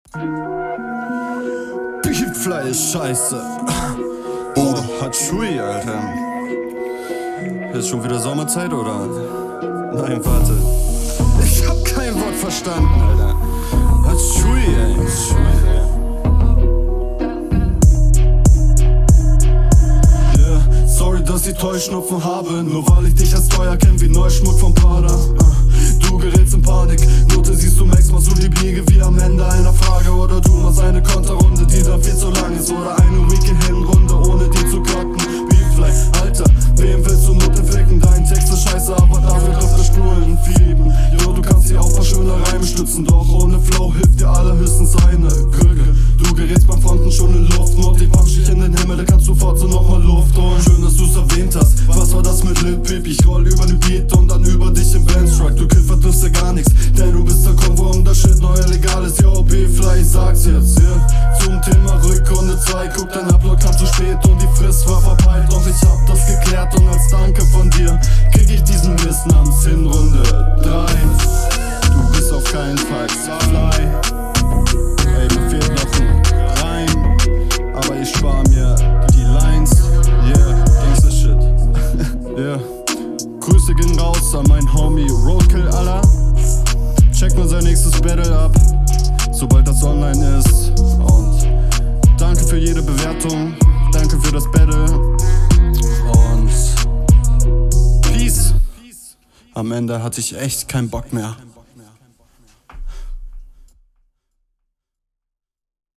diese runde holst du dir ziemlich easy. klingt viel besser. text ist nicht krass aber …
Das ist soundtechnisch echt gut.